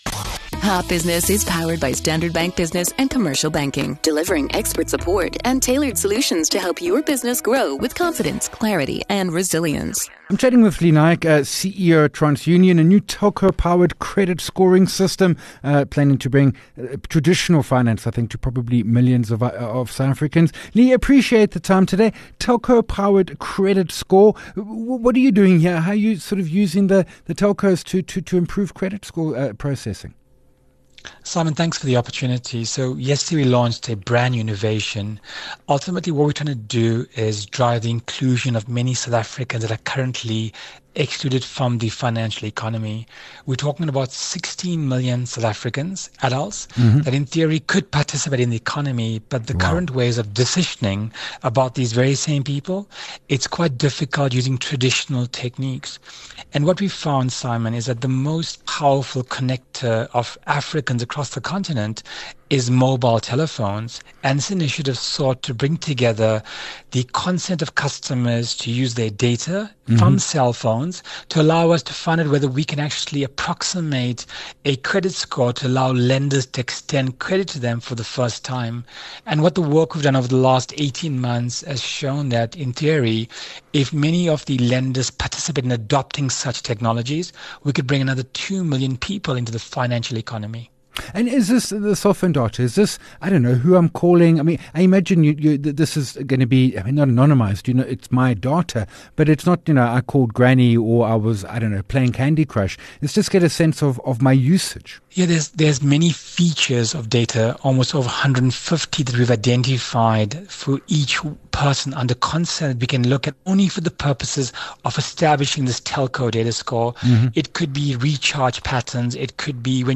WEALTH WEDNESDAYS Topic: New creditworthiness assessment tool: how will it work? Guest